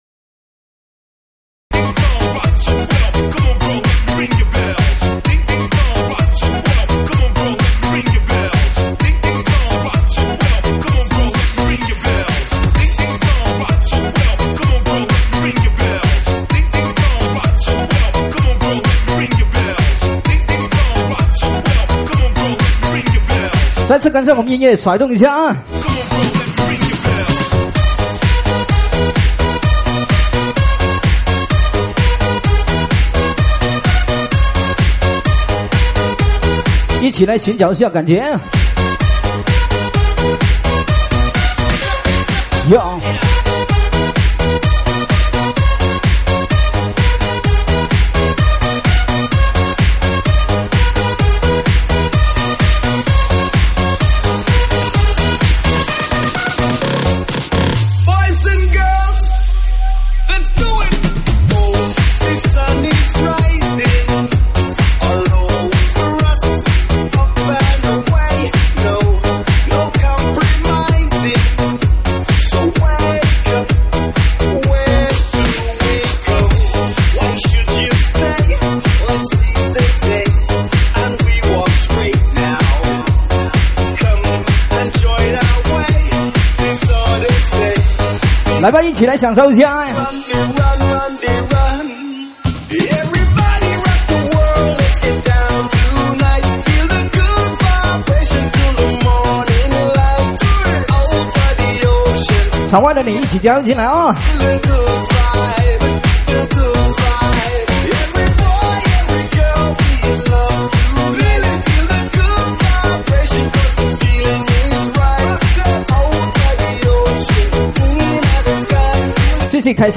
收录于(喊麦现场)